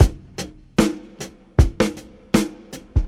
Drum loops
Original creative-commons licensed sounds for DJ's and music producers, recorded with high quality studio microphones.
77-bpm-drum-loop-g-sharp-key-QaI.wav